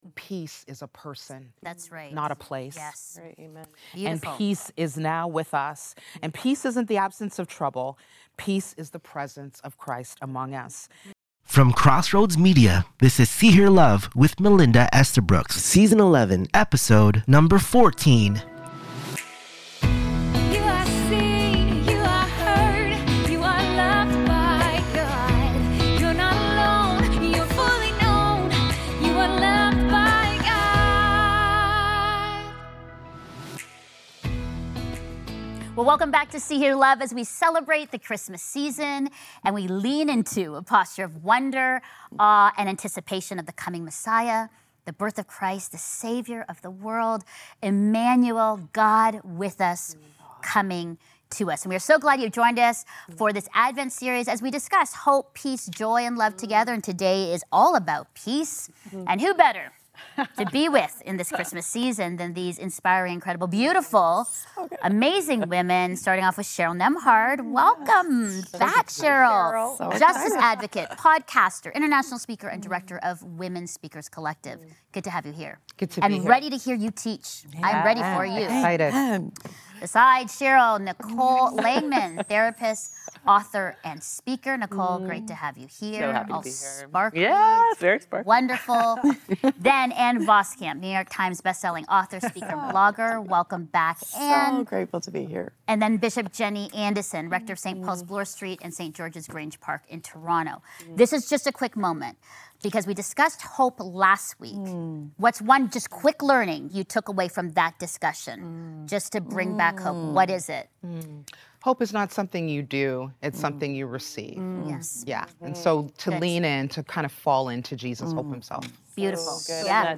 In this Advent conversation, the panel explores why true peace can only be found in a living relationship with Jesus and how internal Shalom transforms every external relationship.